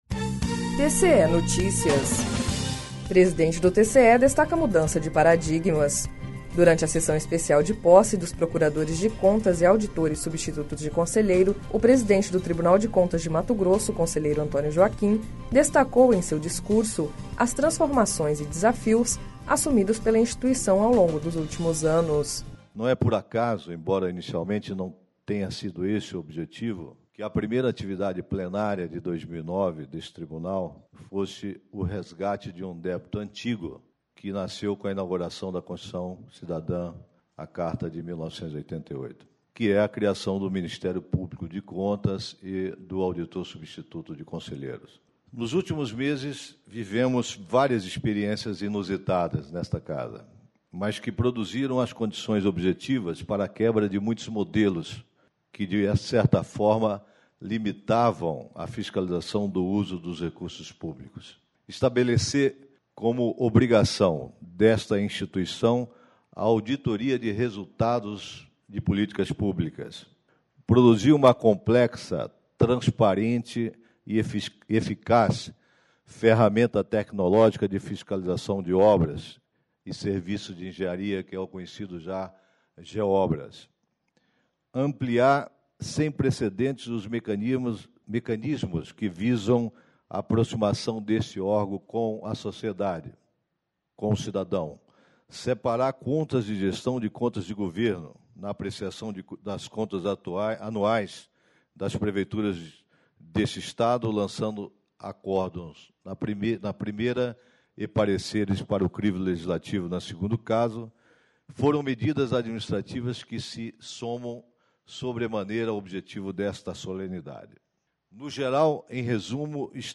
Durante seu pronunciamento naa sessão especial de posse, o conselheiro presidente Antonio Joaquimdestacou as transformações e desafios assumidos pela instituição ao longos dos últimos anos
Confira a íntegra do discurso do conselheiro presidente Antonio Joaquim em arquivo anexo.